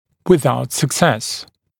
[wɪ’ðaut sək’ses][уи’заут сэк’сэс]безуспешно